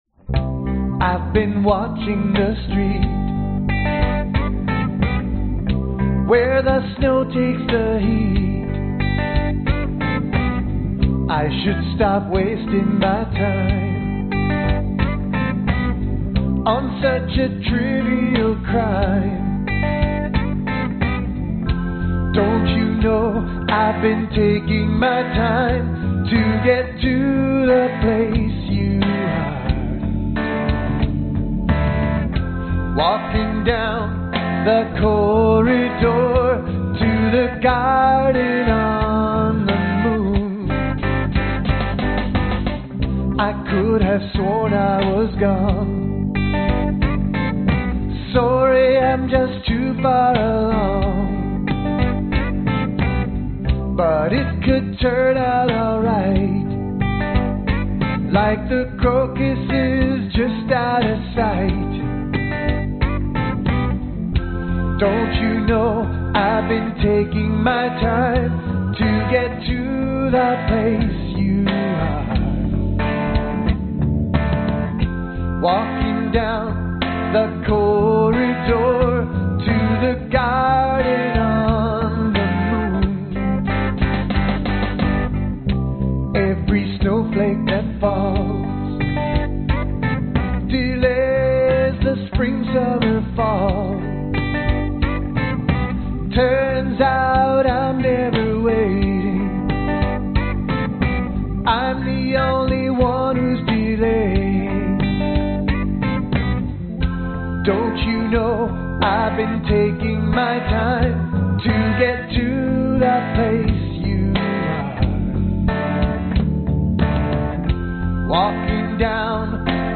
Tag: 贝斯 吉他 男声 风琴 原创歌曲